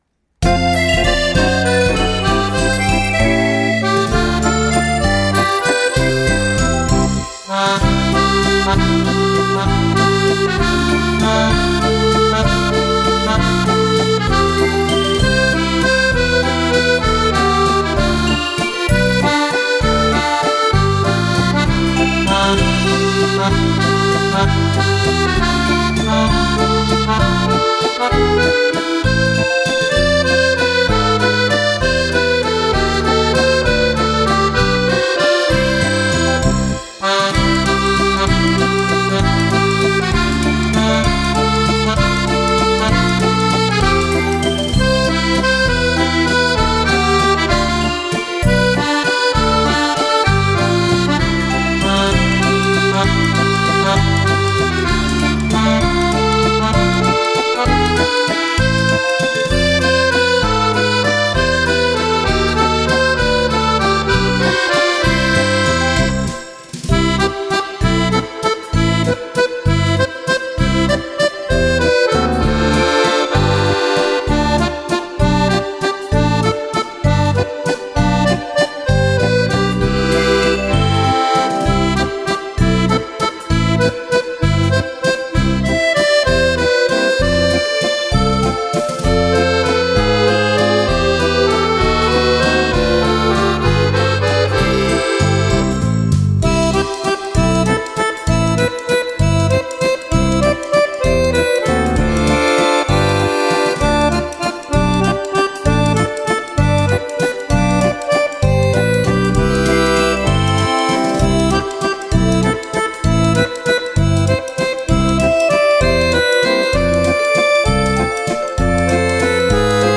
Brano facile per fisarmonica.